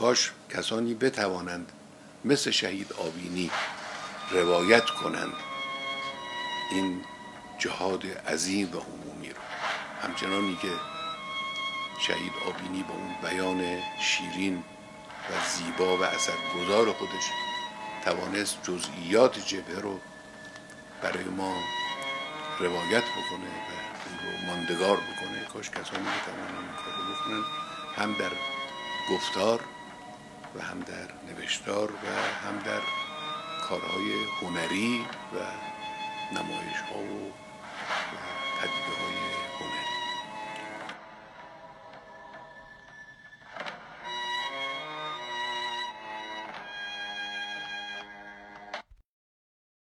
بیستم فروردین 1372 یادآور عروج سید شهیدان اهل قلم و هنرمند فرزانه، حاج سیدمرتضی آوینی، نویسنده توانا، راوی روایت فتح، سردبیر مجله ادبی و هنری سوره و مسئول واحد تلویزیونی حوزه هنری است، به همین مناسبت در ادامه پادکستی با سخنان رهبر معظم انقلاب حضرت آیت‌الله خامنه‌ای تقدیم مخاطبان گرامی ایکنا می‌شود.